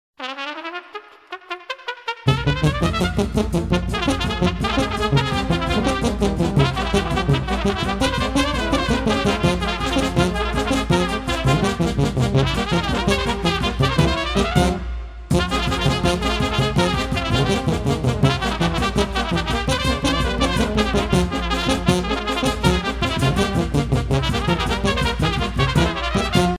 danse : sîrba (Roumanie)
Pièce musicale éditée